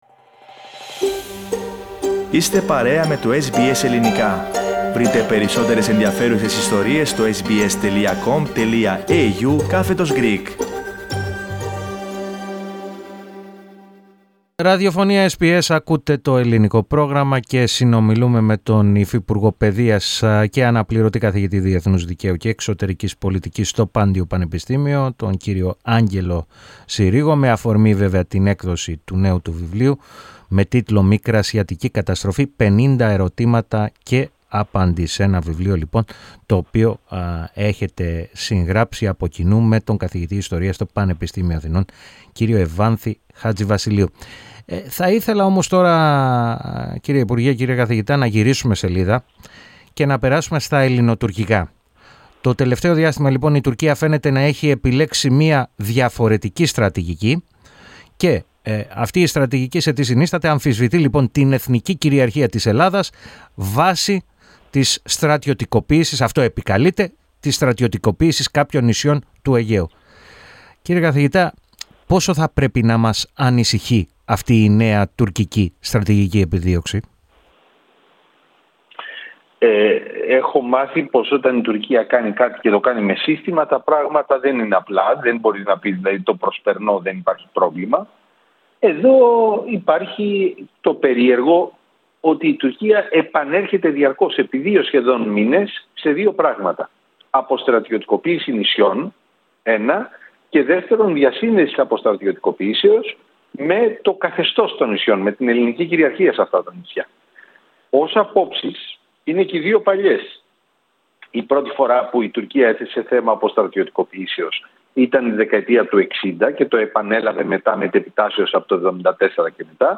Για τις τελευταίες εξελίξεις στις ελληνοτουρκικές σχέσεις, μίλησε στο Ελληνικό Πρόγραμμα της ραδιοφωνίας SBS, ο υφυπουργός Παιδείας, και Αναπληρωτής καθηγητής Διεθνούς Δικαίου και Εξωτερικής Πολιτικής στο Πάντειο Πανεπιστήμιο, Άγγελος Συρίγος.